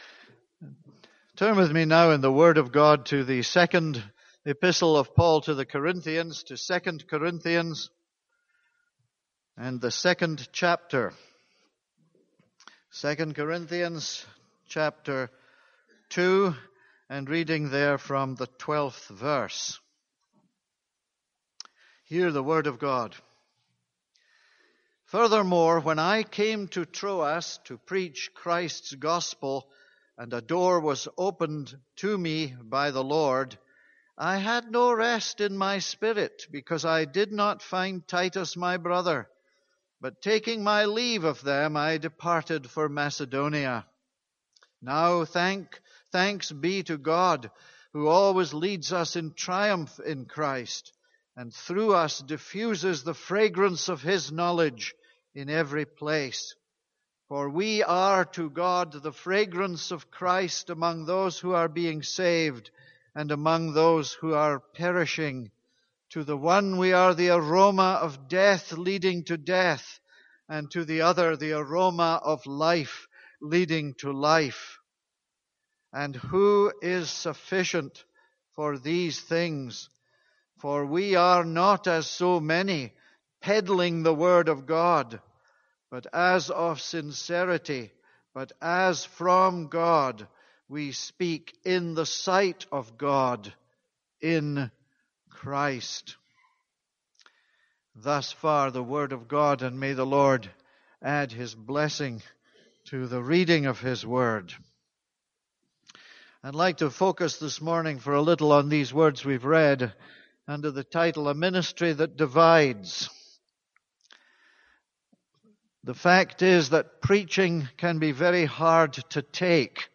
This is a sermon on 2 Corinthians 2:12-17.